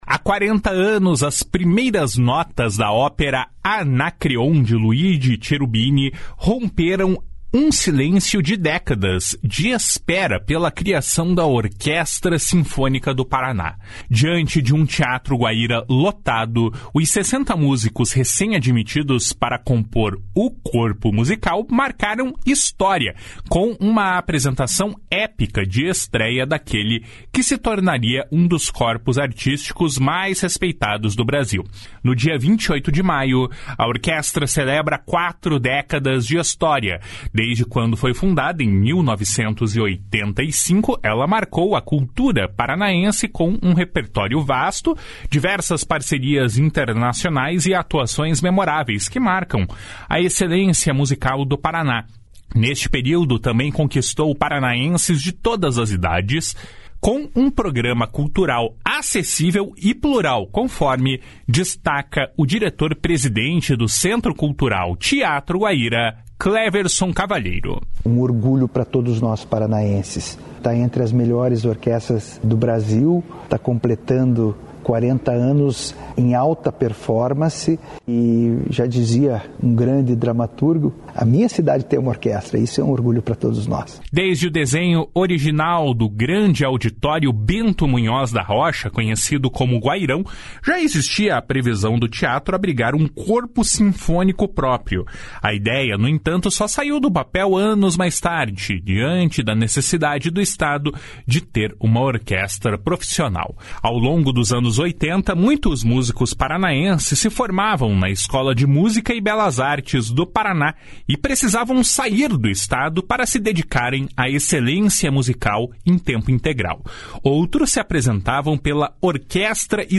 Esta reportagem integra a série especial produzida pela Agência Estadual de Notícias em comemoração aos 40 anos da Orquestra Sinfônica do Paraná.